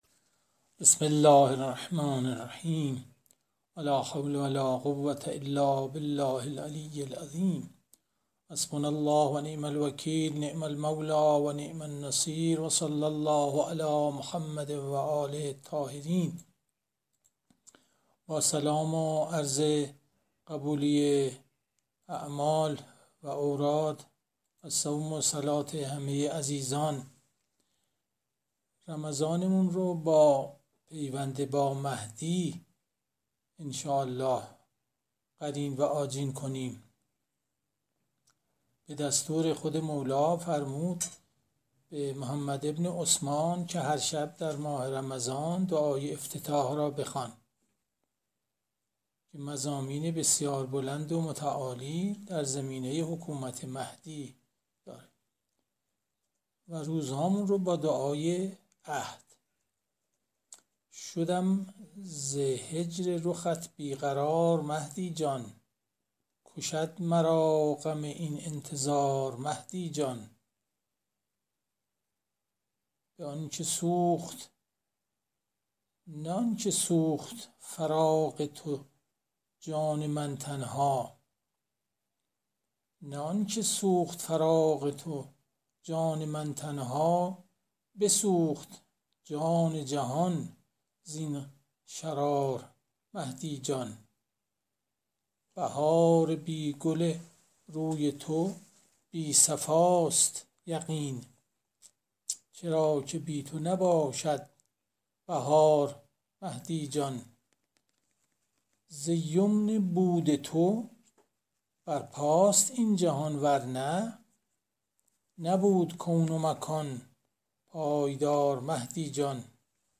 جلسات مجازی